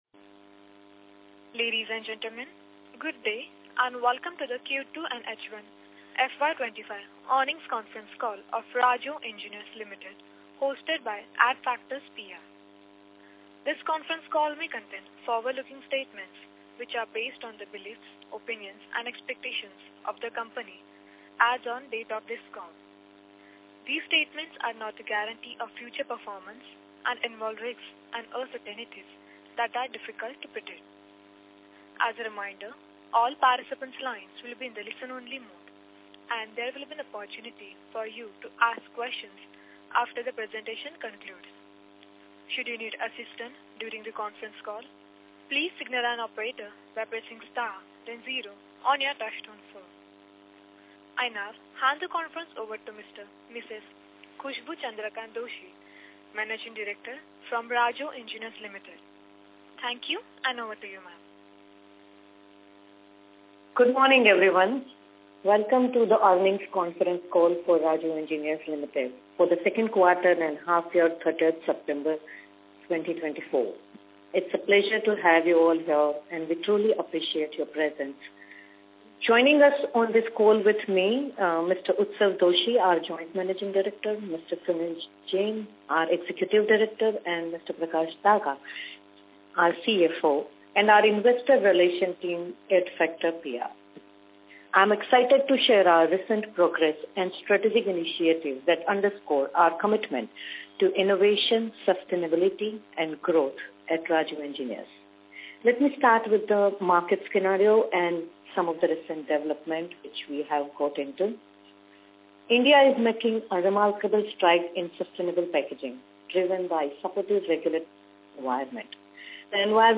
recording-of-earning-conference-call-Q2-FY25.mp3